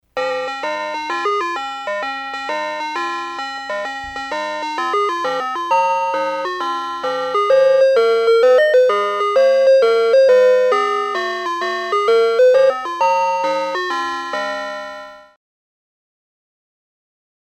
SFX – ICE CREAM TRUCK (POP GOES THE WEASEL)
SFX-ICE-CREAM-TRUCK-(POP-GOES-THE-WEASEL).mp3